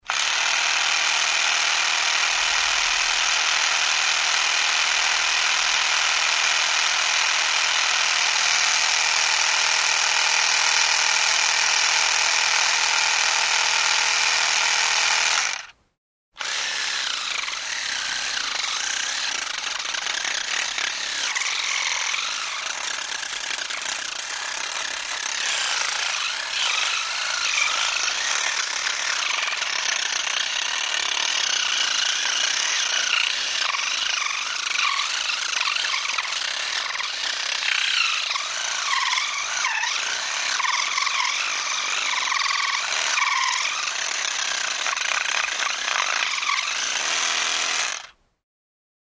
فرشاة الأسنان الكهربائية: